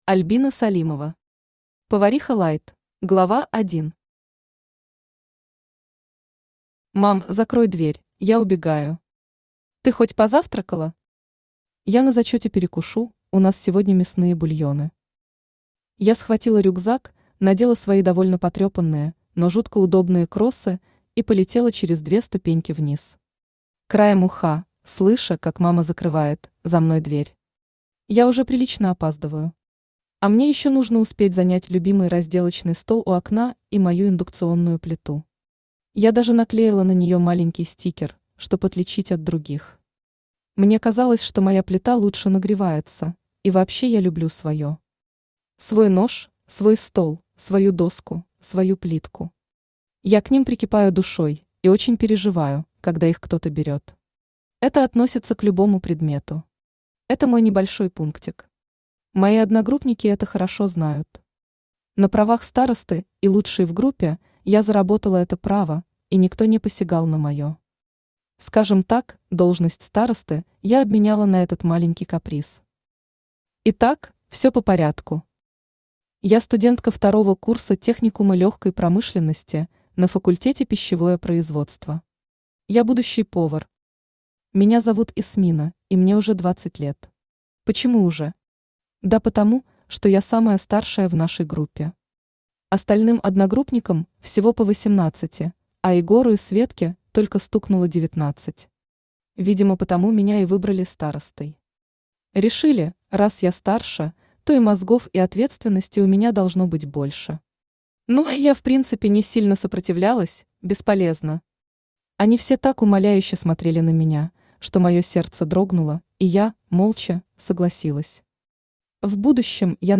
Аудиокнига Повариха light | Библиотека аудиокниг
Aудиокнига Повариха light Автор Альбина Салимова Читает аудиокнигу Авточтец ЛитРес.